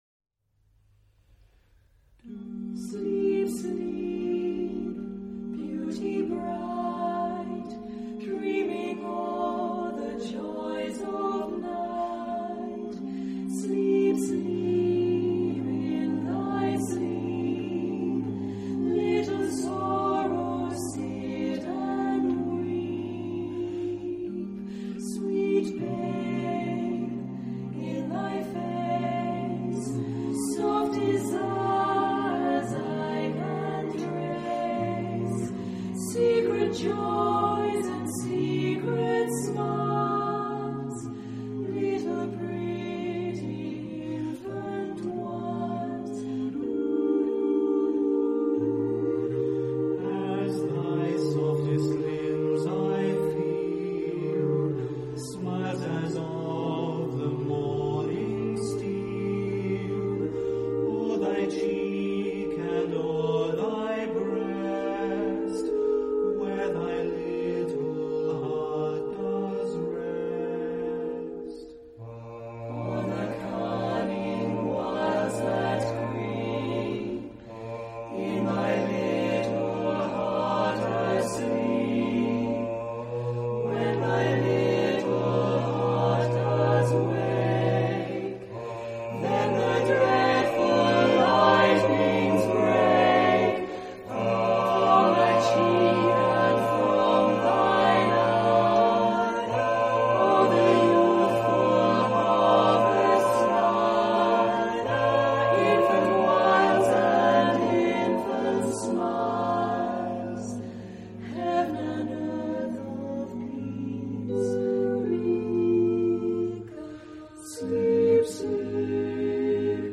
Genre-Style-Form: Secular ; Choir ; Poem ; Lullaby
Mood of the piece: poetic
Type of Choir: SSAATTB  (7 mixed voices )
Tonality: E flat major